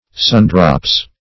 Sundrops \Sun"drops`\, n. [Sun + drop.] (Bot.)